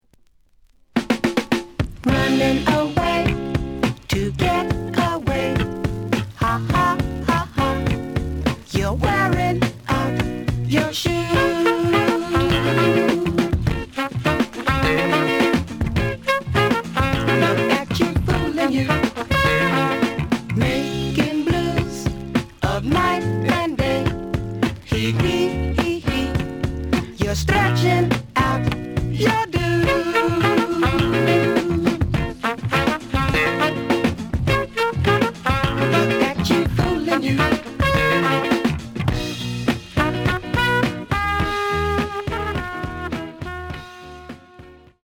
The audio sample is recorded from the actual item.
●Genre: Funk, 70's Funk
Slight noise on A side.)